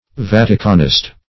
Search Result for " vaticanist" : The Collaborative International Dictionary of English v.0.48: Vaticanist \Vat"i*can*ist\, n. One who strongly adheres to the papal authority; an ultramontanist.